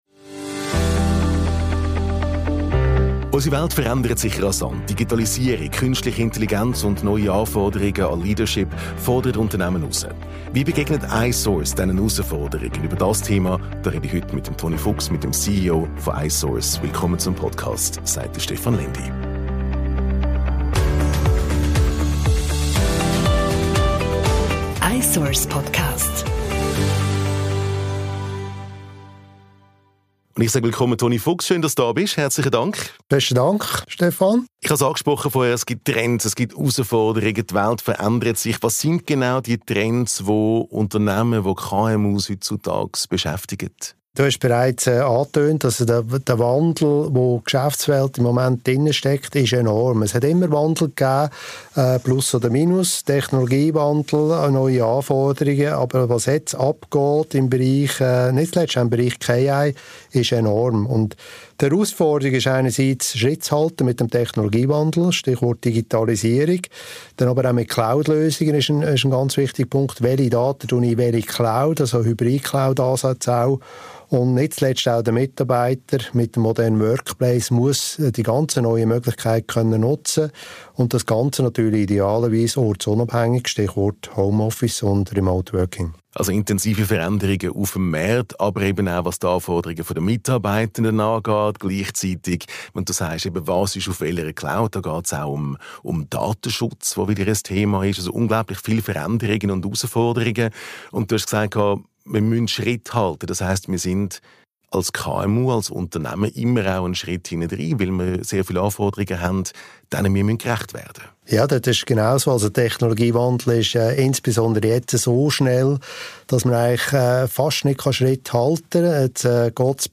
Herausforderungen, KI und Leadership – ein Gespräch